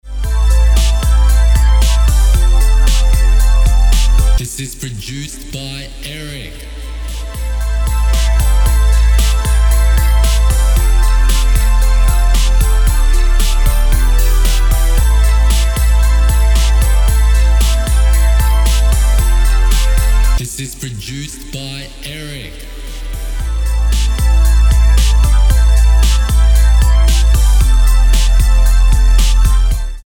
Channelling West Coast vibes.
Key: A minor Tempo: 114BPM Time: 4/4 Length: 2:48